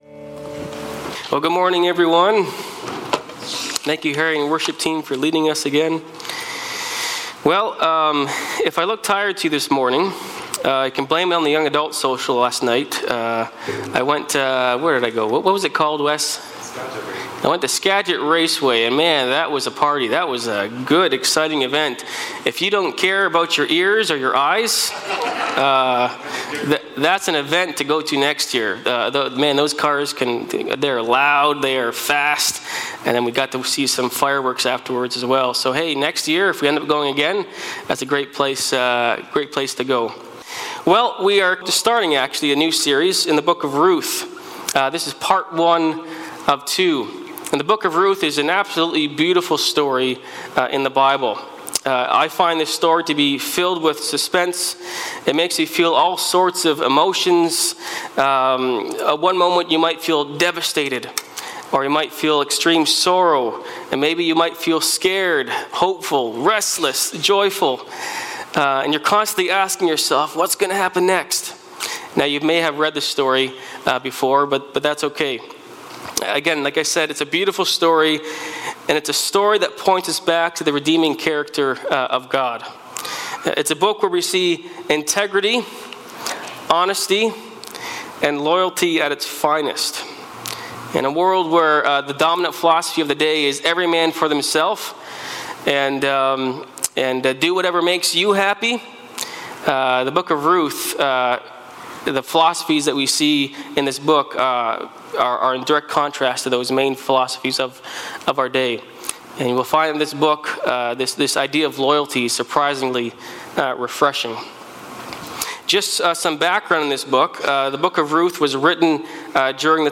Sermons | Grace Church